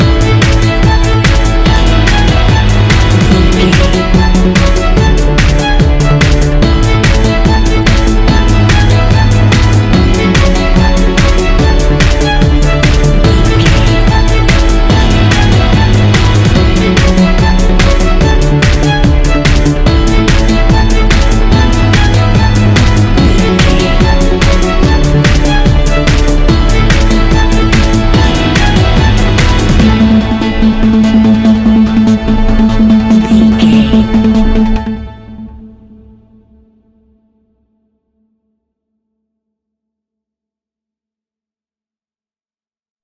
Ionian/Major
energetic
uplifting
hypnotic
drum machine
synthesiser
violin
piano
instrumentals
synth leads
synth bass